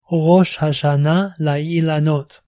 rosh-hashanah-lailanot.mp3